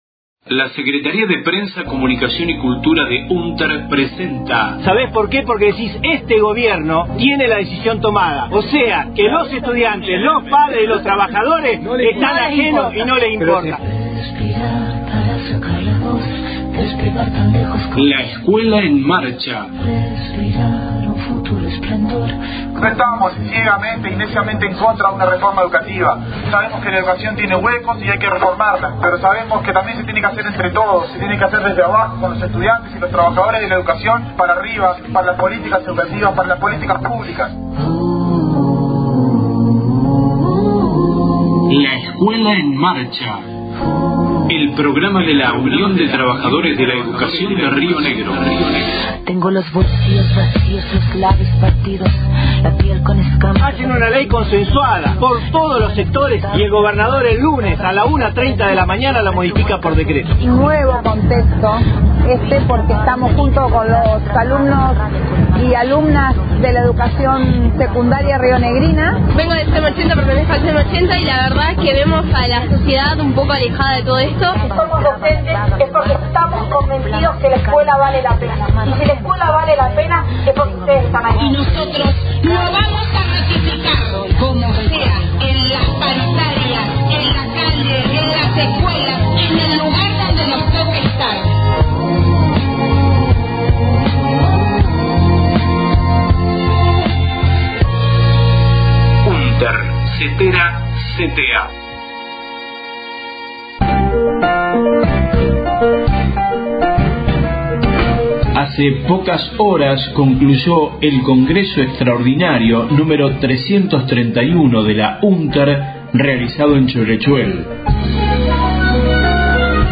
audios del CCCXXXI Congreso Extraordinario, realizado en Choele Choel el 13/07/17, con la presencia de todas las Seccionales de UnTER.